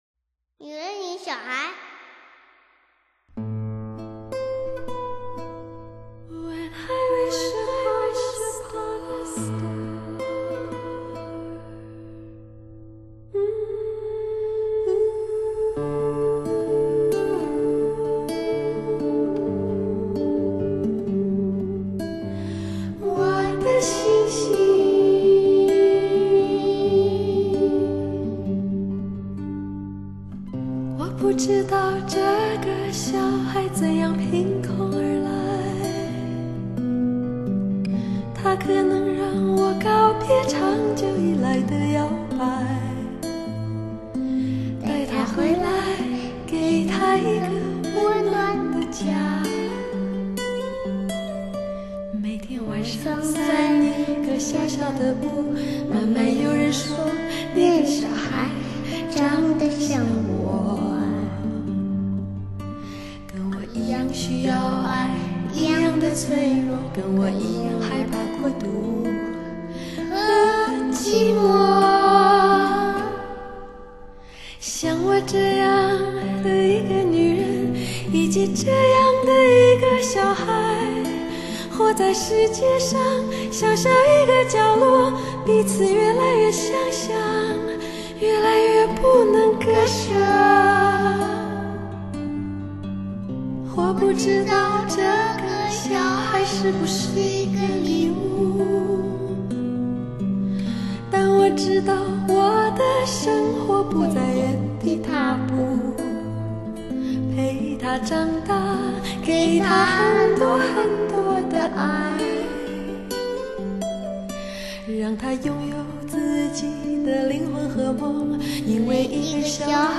准确地说，女 儿的声音，是一种充满灵气的和声。
听这样的 歌让人感到一种完全的温暖和快乐。